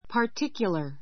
particular pə r tíkjulə r パ ティ キュら 形容詞 ❶ 特別の for a particular reason for a particular reason 特別な理由で I have nothing particular to say.